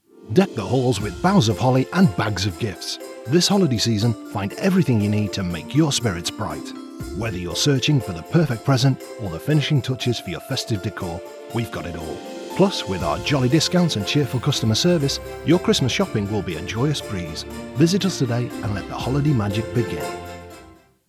Radio Ad - Christmas Shopping
English - United Kingdom